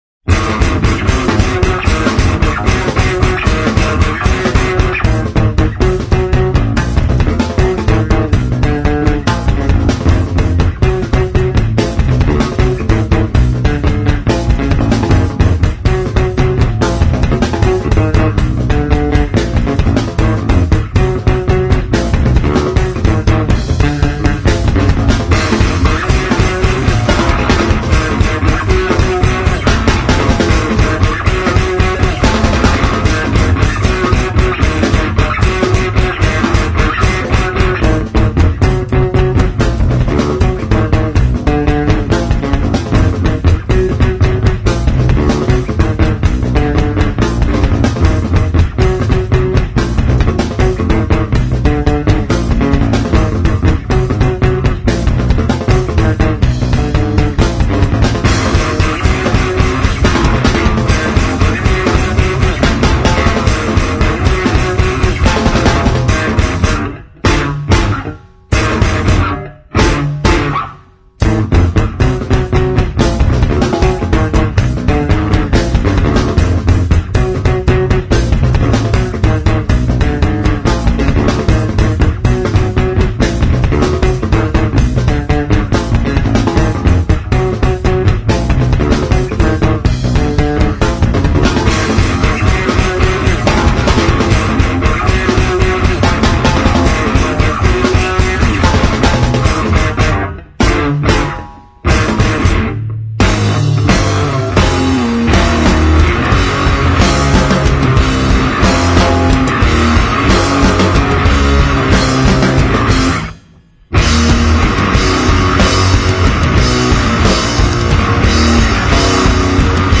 这个二人乐队